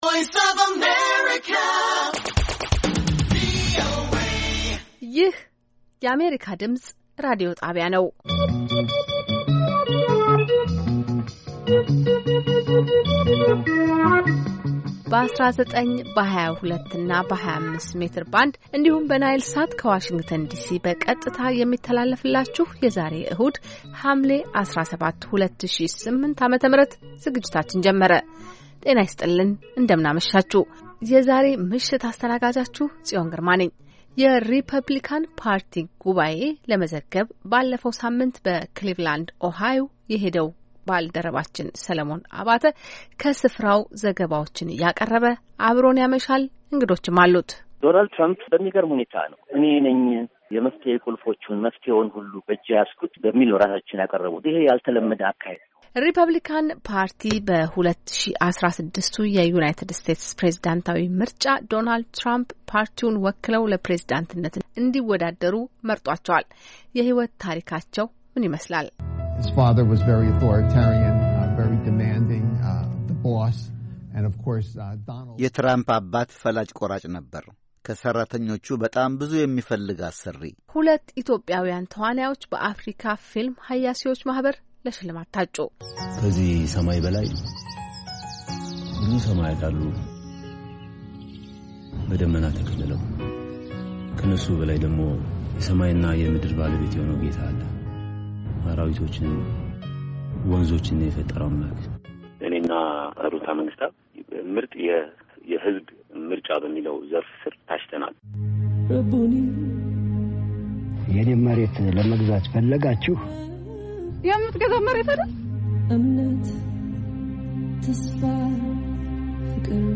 ቪኦኤ በየዕለቱ ከምሽቱ 3 ሰዓት በኢትዮጵያ ኣቆጣጠር ጀምሮ በአማርኛ፣ በአጭር ሞገድ 22፣ 25 እና 31 ሜትር ባንድ የ60 ደቂቃ ሥርጭቱ ዜና፣ አበይት ዜናዎች ትንታኔና ሌሎችም ወቅታዊ መረጃዎችን የያዙ ፕሮግራሞች ያስተላልፋል። ዕሁድ፡- ራዲዮ መፅሔት፣ መስተዋት (የወጣቶች ፕሮግራም) - ሁለቱ ዝግጅቶች በየሣምንቱ ይፈራረቃሉ፡፡